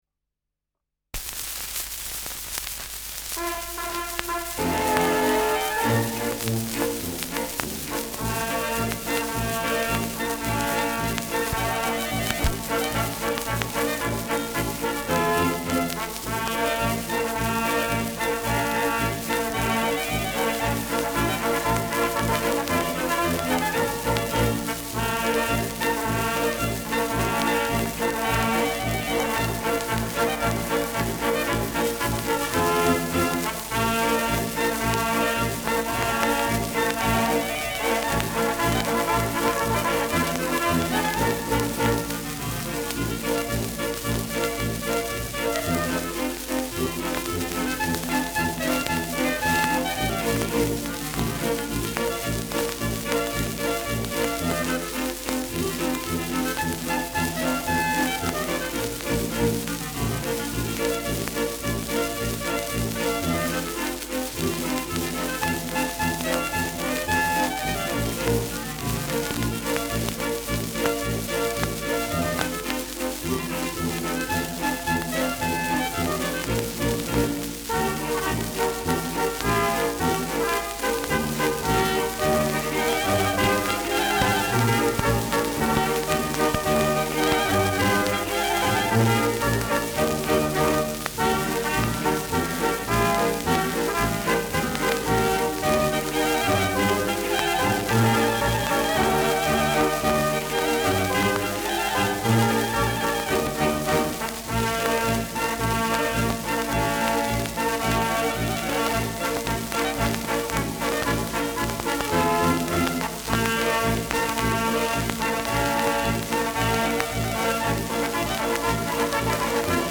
Schellackplatte
präsentes Rauschen : leichtes Knacken
Dachauer Bauernkapelle (Interpretation)